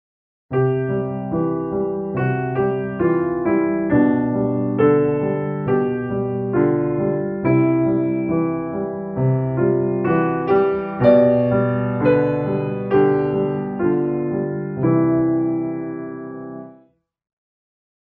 allé un exemple que je viens d'improviser...en do majeur et j'ai mi un fa#...j'ai beau faire...j'entend pas de lydien là....c'ets une appogiature point barre.
...donc là j'ai mis 2 fois un fa#